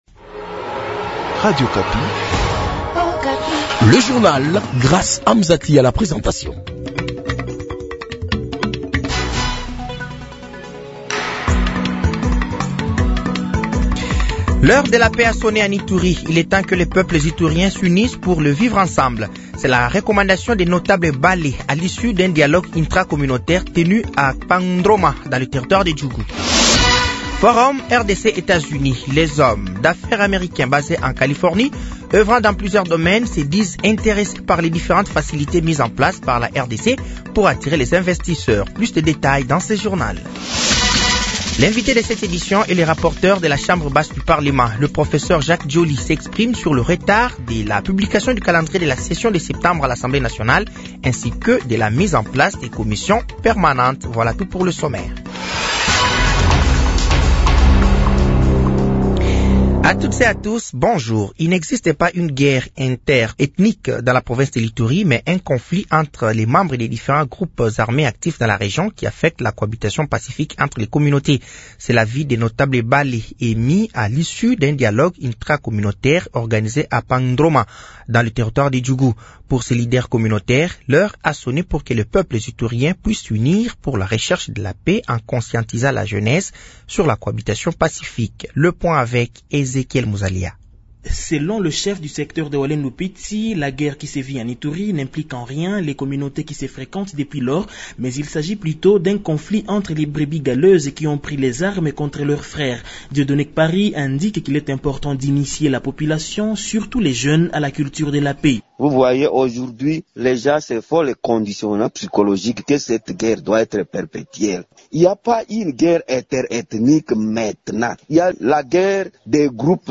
Journal matin
Journal français de 8h de ce jeudi 03 octobre 2024